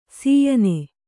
♪ sīyane